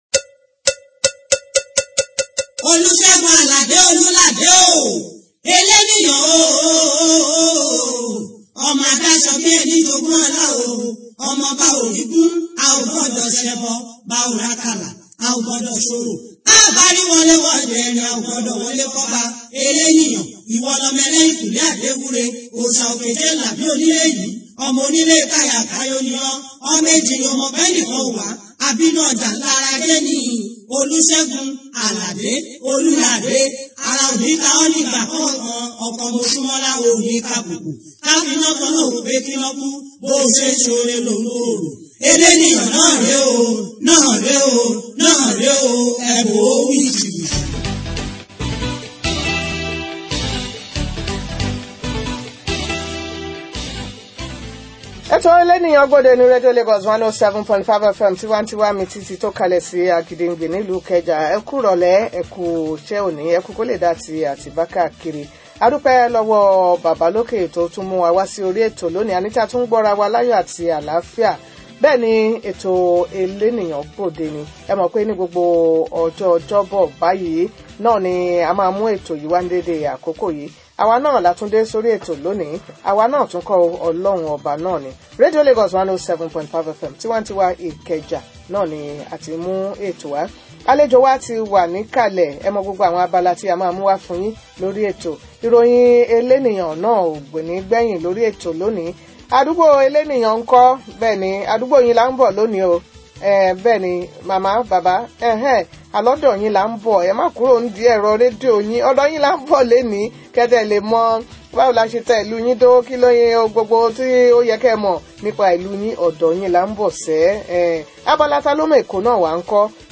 Guest: Hon Ipoola Ahmed Omisore, Former Representative of Ifako Ijaiye Constituency 2
Venue: Radio Lagos 107.5 FM